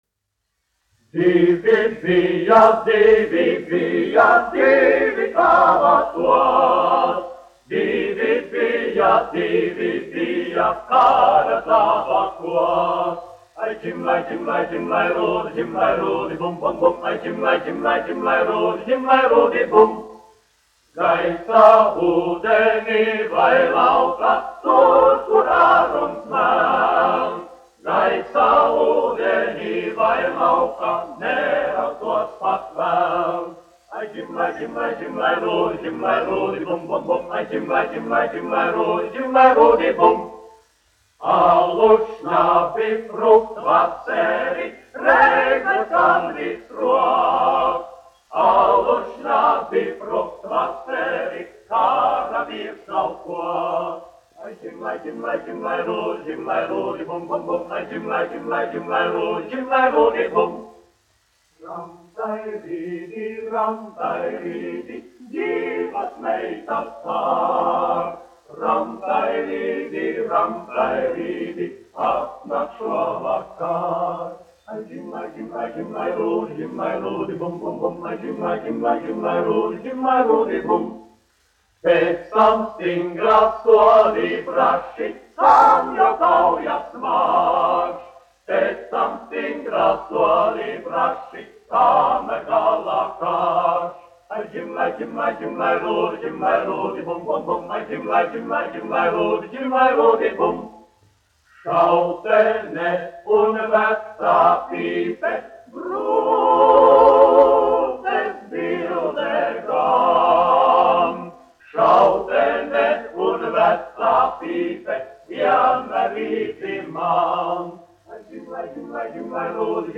1 skpl. : analogs, 78 apgr/min, mono ; 25 cm
Kara dziesmas
Vokālie ansambļi
Skaņuplate